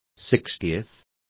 Complete with pronunciation of the translation of sixtieths.